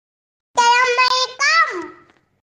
Nada dering Assalamualaikum anak kecil
Kategori: Nada dering
nada-dering-assalamualaikum-anak-kecil-id-www_tiengdong_com.mp3